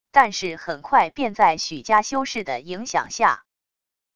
但是很快便在许家修士的影响下wav音频生成系统WAV Audio Player